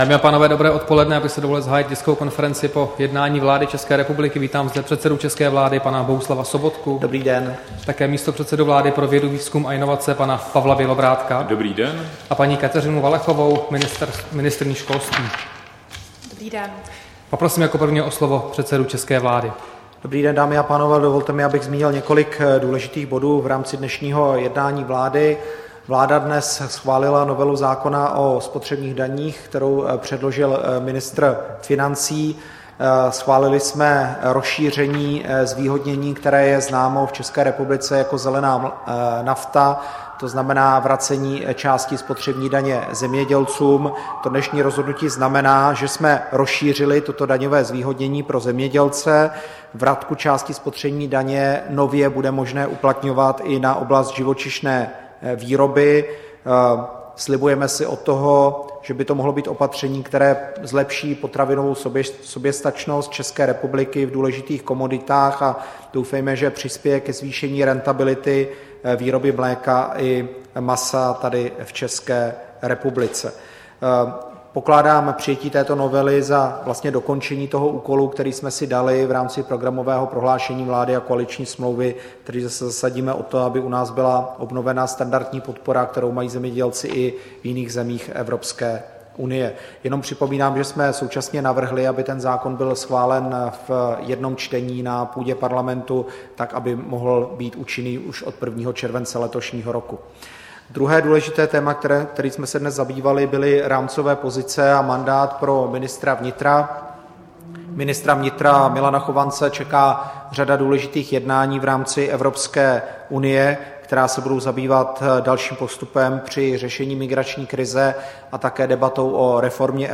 Tisková konference po jednání vlády, 18. dubna 2016